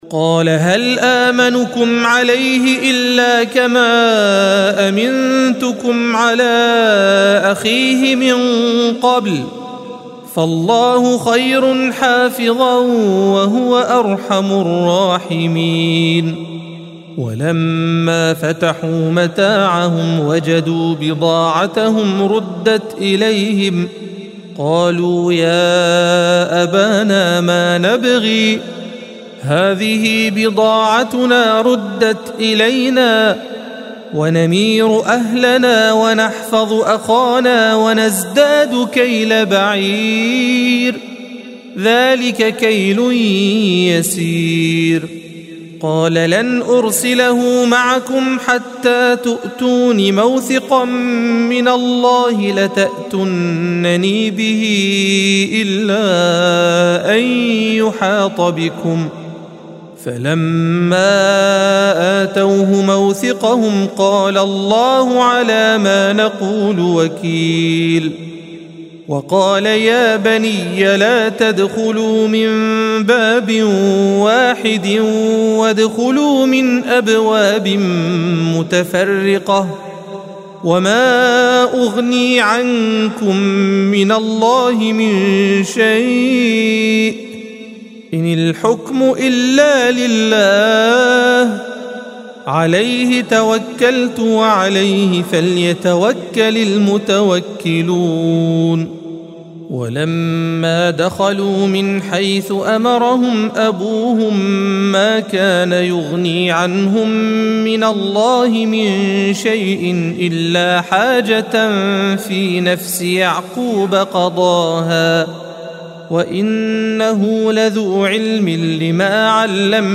الصفحة 243 - القارئ